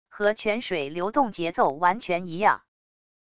和泉水流动节奏完全一样wav音频